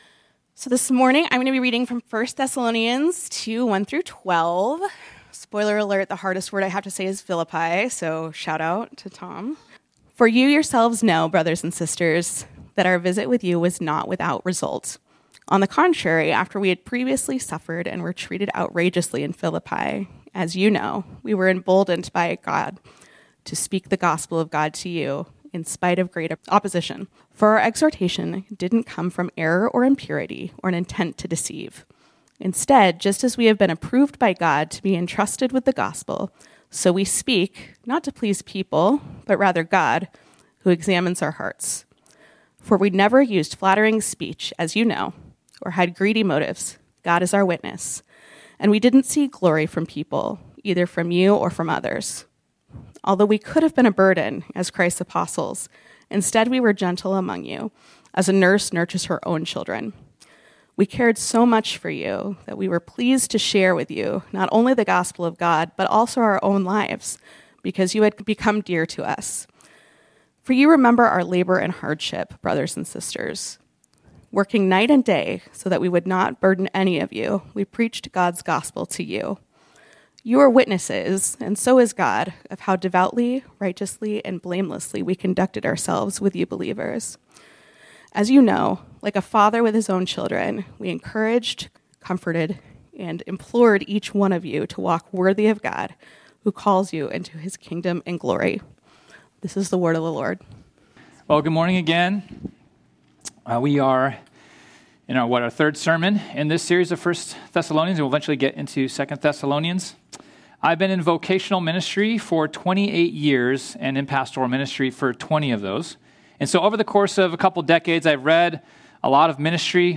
This sermon was originally preached on Sunday, June 22, 2025.